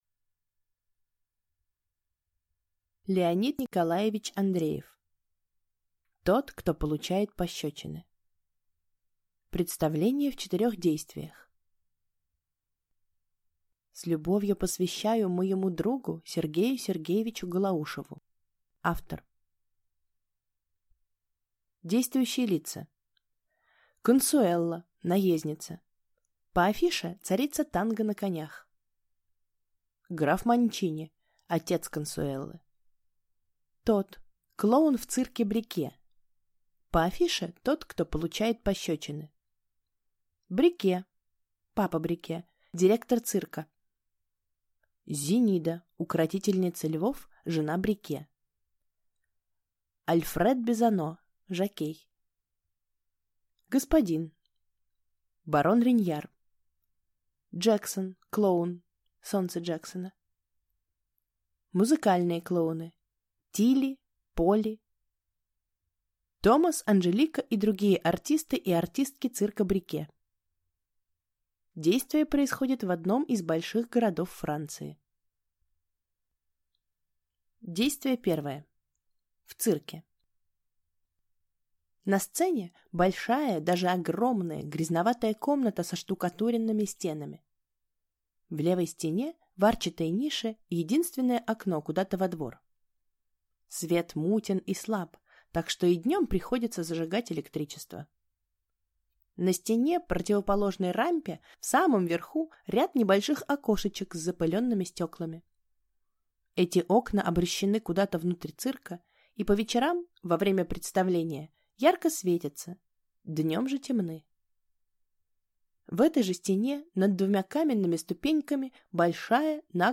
Аудиокнига Тот, кто получает пощечины | Библиотека аудиокниг